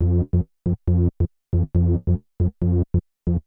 DANGER BAS-L.wav